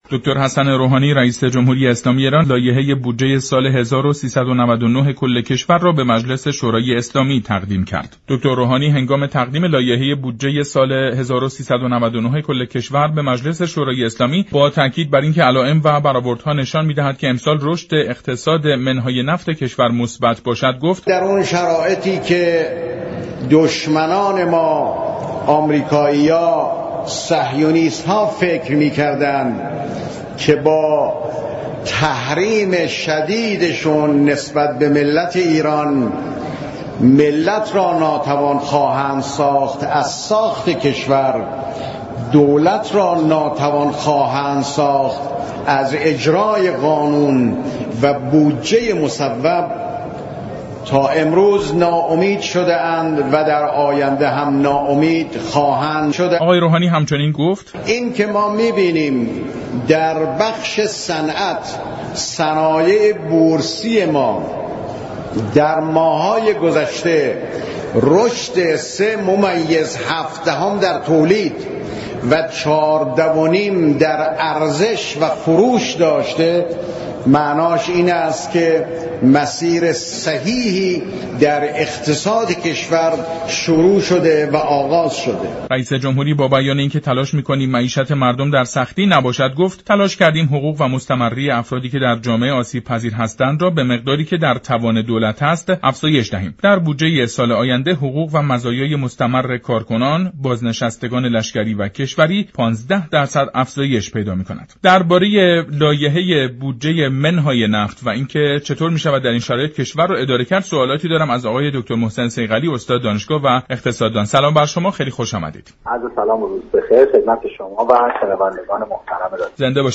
برنامه جهان سیاست شنبه تا چهارشنبه هر هفته ساعت 15:30 از رادیو ایران پخش می شود.